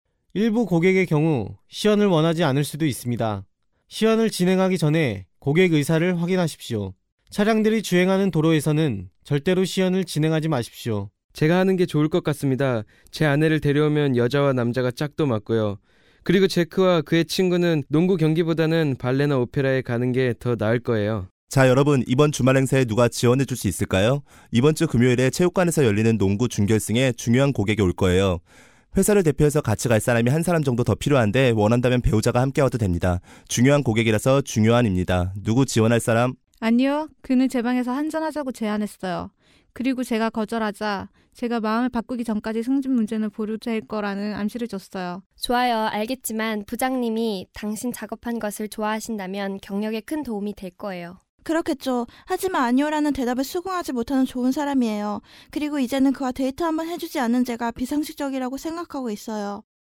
Sprecher chinesisch, Chinese, Cantonese, Mandarin, Japanese, Korean, Voice over
Sprechprobe: Industrie (Muttersprache):